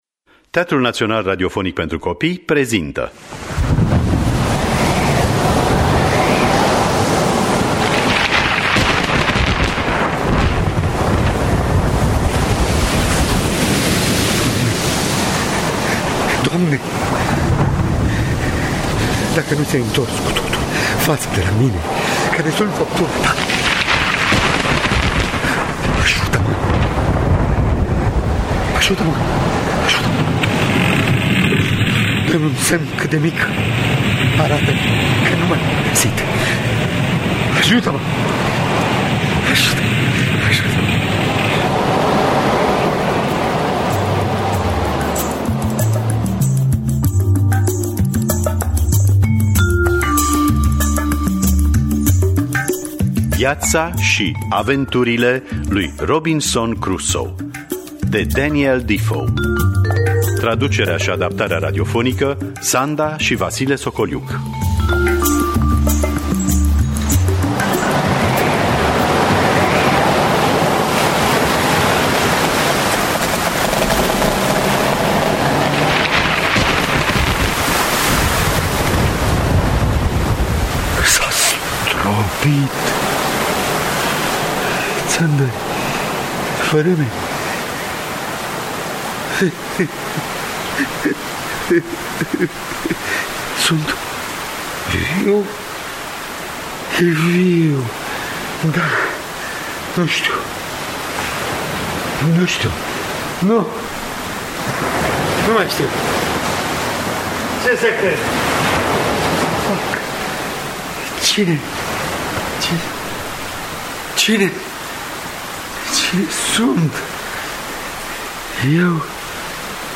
Viaţa şi aventurile lui Robinson Crusoe de Daniel Defoe – Teatru Radiofonic Online